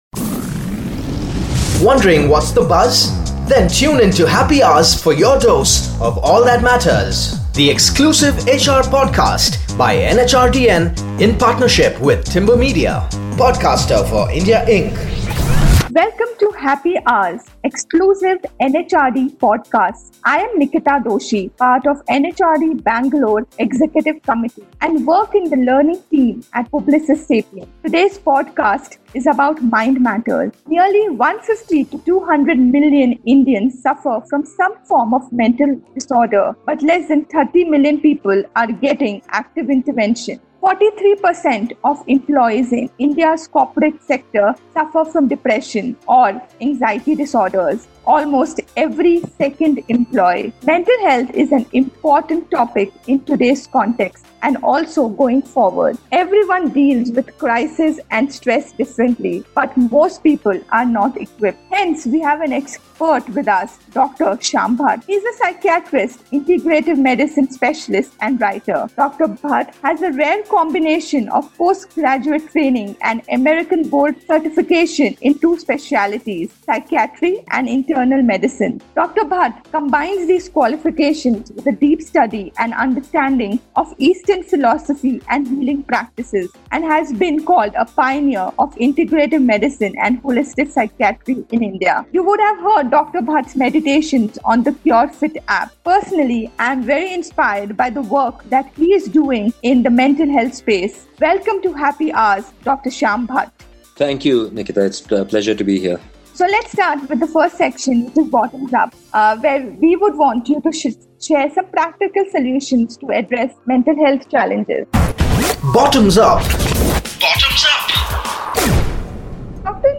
The podcast ends with a very powerful 5-minute meditation to help you build your resilience and balance.